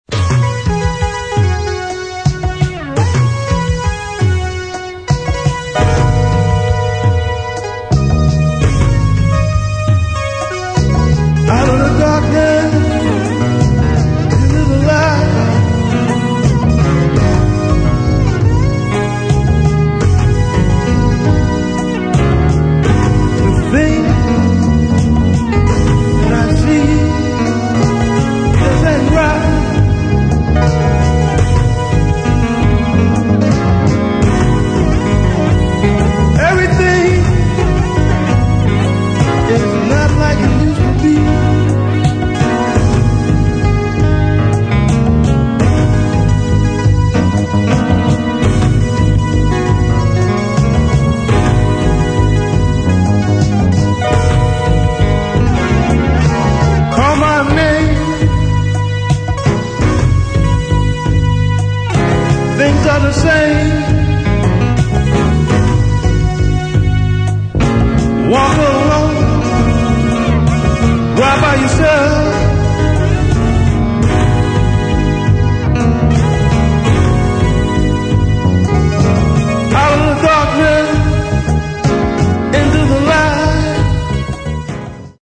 Oddball funk biz!
Disco Soul Funk